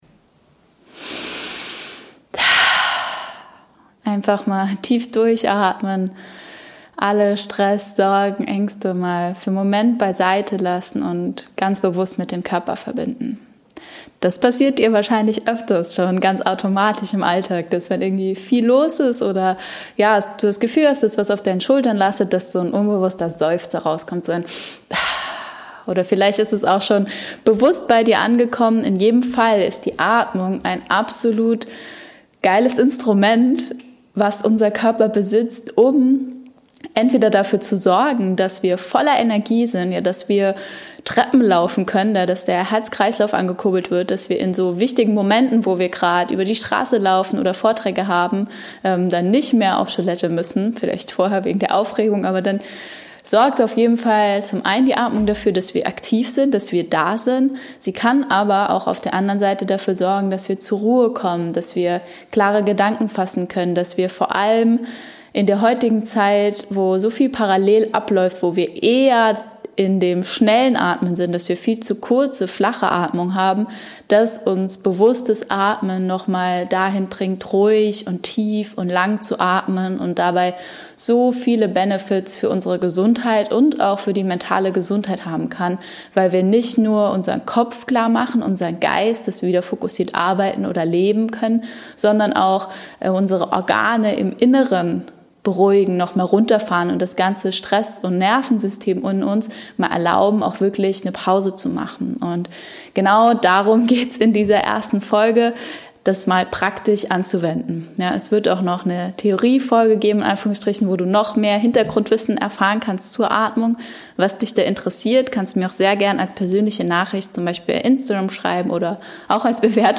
Das erwartet dich: eine Atembeobachtung zum Mitmachen: Schritt für Schritt bildhaft angeleitet, sodass du dich wunderbar fokussieren und zur Ruhe kommen kannst ein paar Minuten Stille, um deine Energien wieder aufzuladen eine praktische Übung, die du immer wieder auch zwischendurch anwenden kannst (in voller Länge oder gekürzt) Wir hoffen sehr, dass dir die Übung einen Moment der innere Ruhe und Fokus schenkt und du diese auch weiter in deinen Alltag tragen kannst.